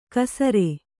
♪ kasare